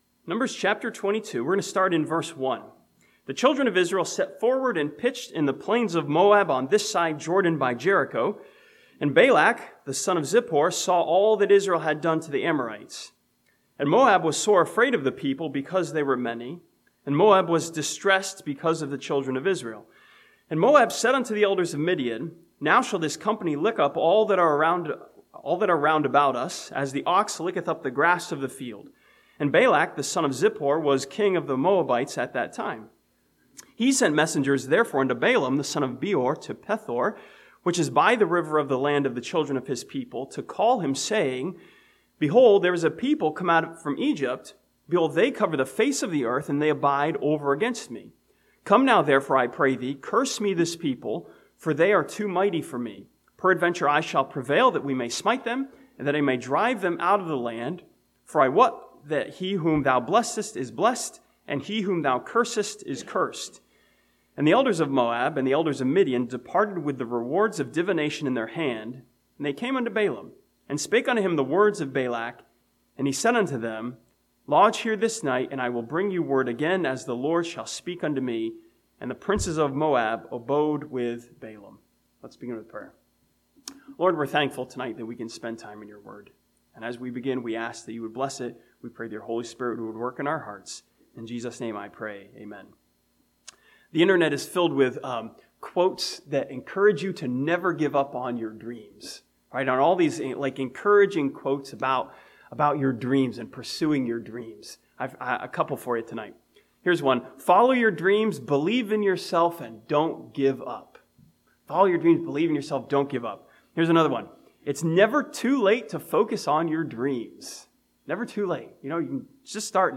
This sermon from Numbers chapter 22 looks at Balaam and his pursuit of the wages of unrighteousness as a portrait of greed.